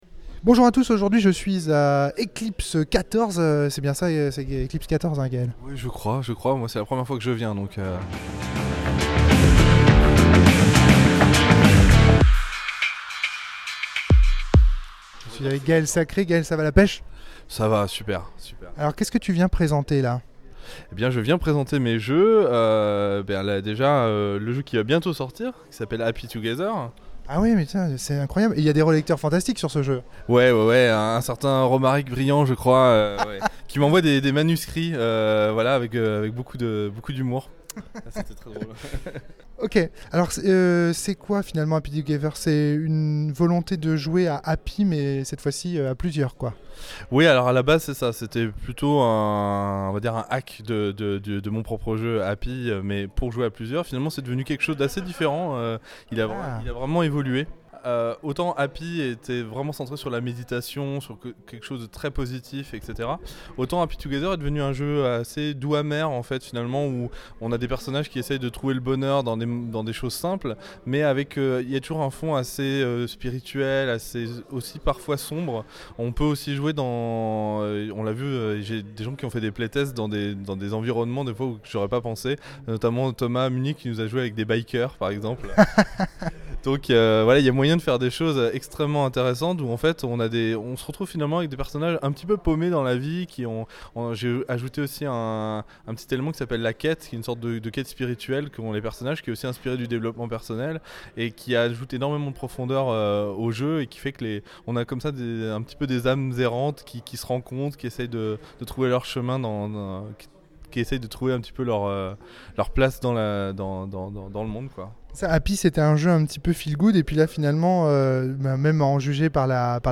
Comme d'habitude, je me suis rendu avec mon micro sur les stands des indés ou des amateurs présents sur place.